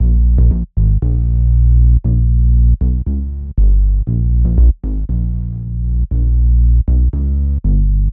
SC_Essentials_Bass_1_Pop_118_bpm_cy8_nFY.wav